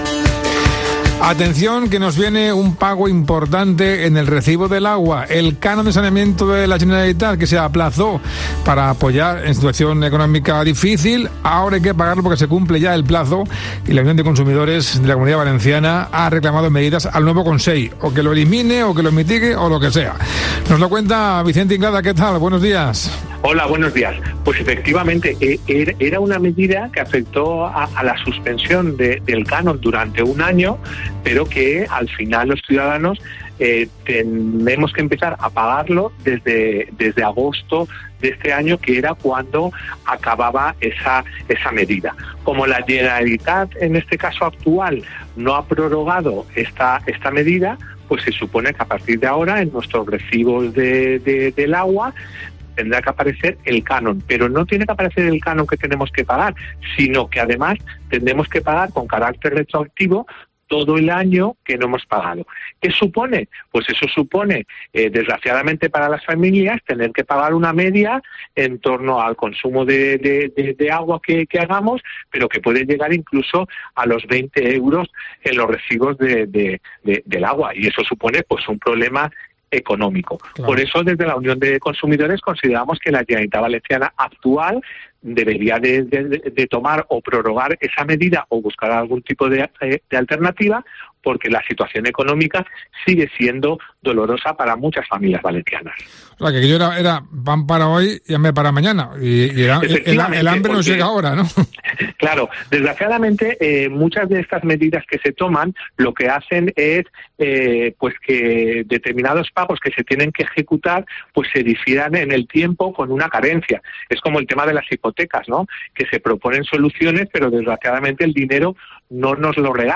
ENTREVISTA| La Unión de Consumidores explica por qué vamos a pagar más en nuestro recibo del agua